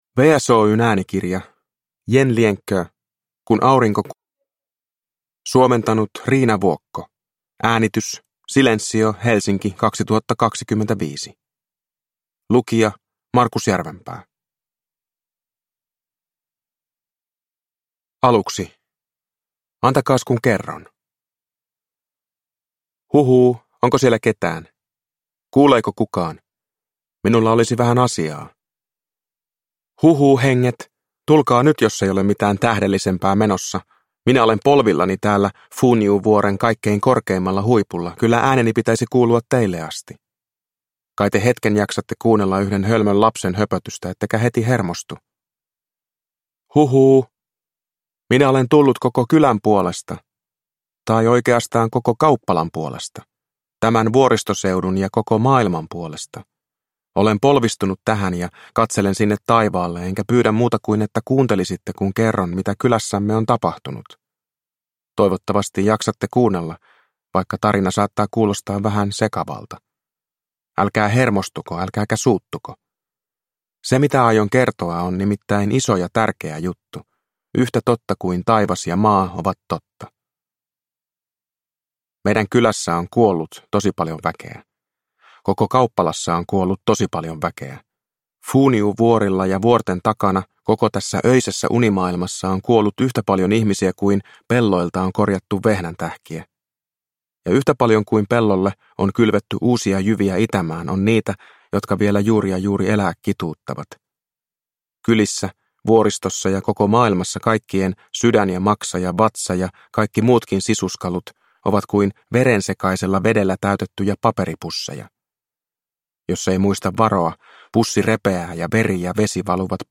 Kun aurinko kuoli – Ljudbok